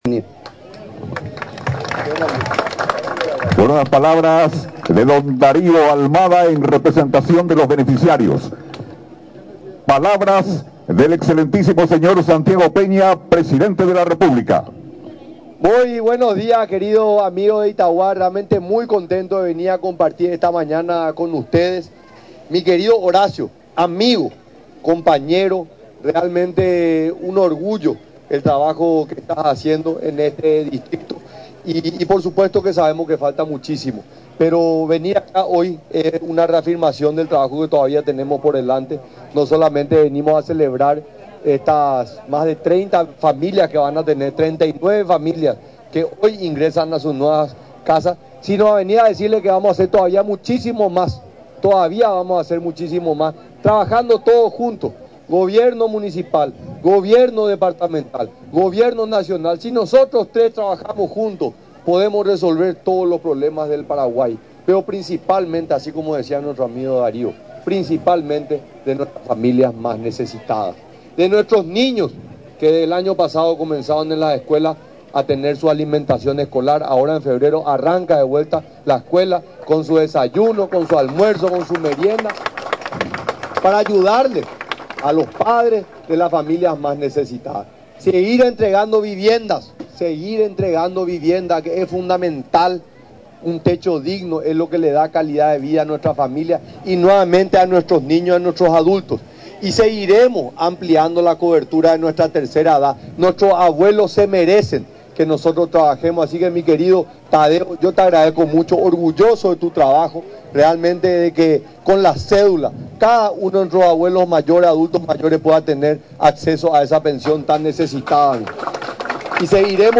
En un acto que contó con la presencia del presidente de la República, Santiago Peña, unas 39 familias fueron beneficiadas con viviendas sociales, a través del programa del Fonavis.
Durante la ceremonia, el mandatario paraguayo, mencionó que no solo fueron a entregas 39 viviendas a las citadas familias beneficiadas, sino a expresar el compromiso del Gobierno con el distrito de Itauguá.